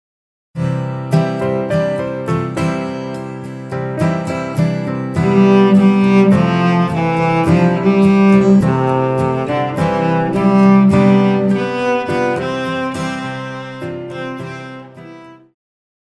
Pop
Cello
Band
Instrumental
Rock,Country
Only backing